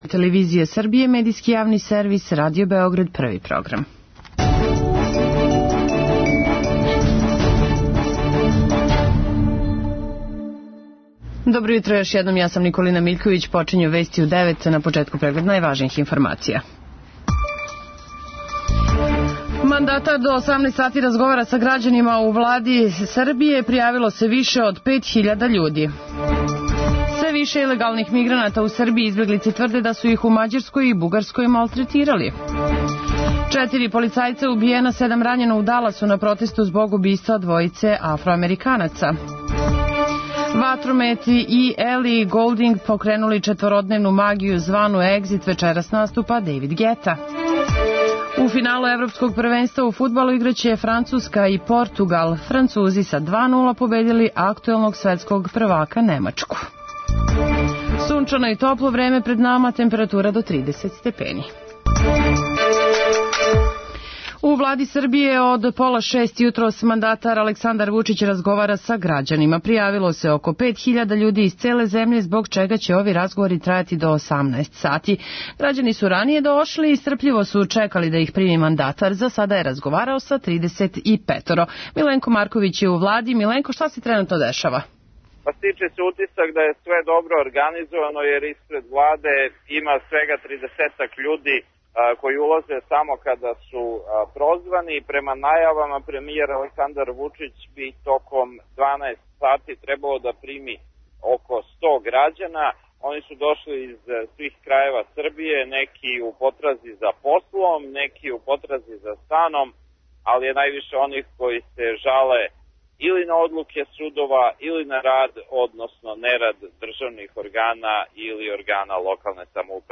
преузми : 3.86 MB Вести у 9 Autor: разни аутори Преглед најважнијиx информација из земље из света.